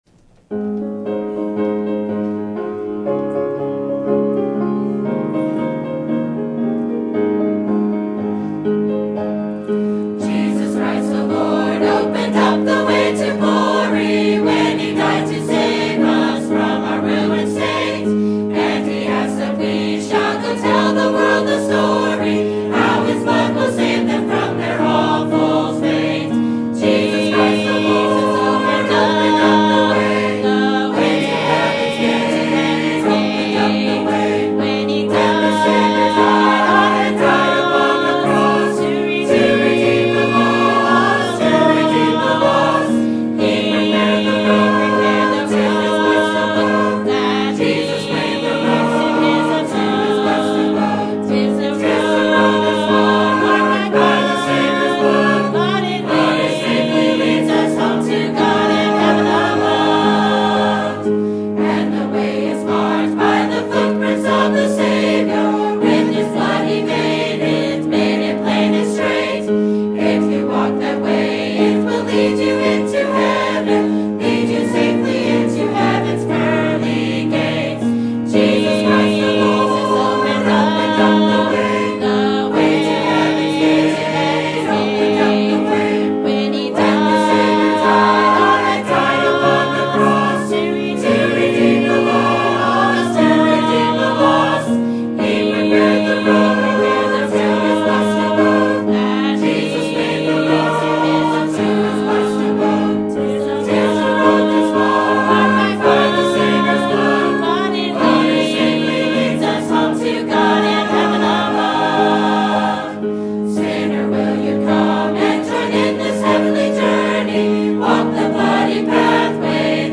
Sermon Topic: Life of Kings and Prophets Sermon Type: Series Sermon Audio: Sermon download: Download (31.25 MB) Sermon Tags: 2 Samuel Kings Prophets Saul